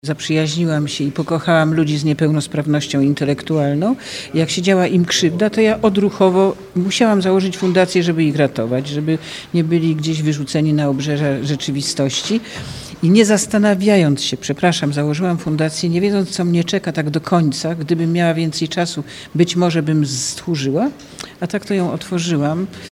Mówi Anna Dymna, aktorka, prezeska i wolontariuszka Fundacji Mimo Wszystko.